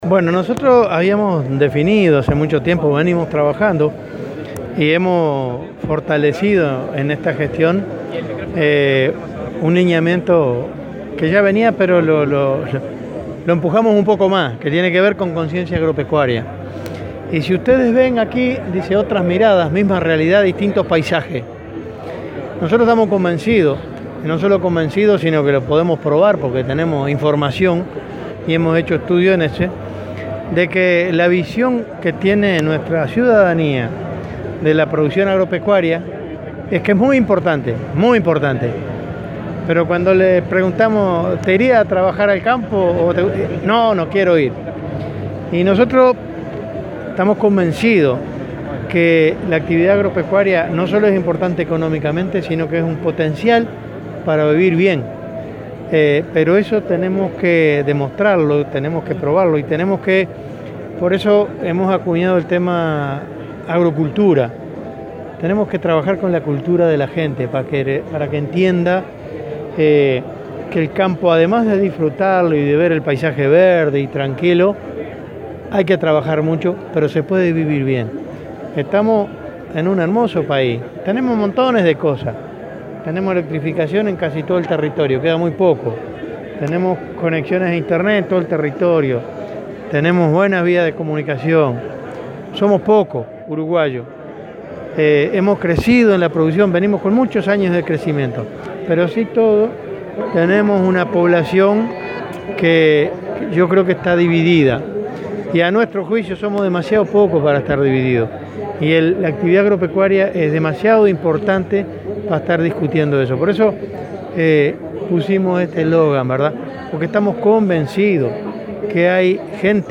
“La Expo Prado es una oportunidad para aprender el trabajo e importancia del sector agroindustrial y la innovación tecnológica, porque, en gran parte, de eso vivimos en Uruguay, expresó el ministro de Ganadería, Enzo Benech, en la inauguración del estand de la cartera, que lleva como premisa “Otras miradas, misma realidad, distintos paisajes”, con el objetivo de generar conciencia agropecuaria.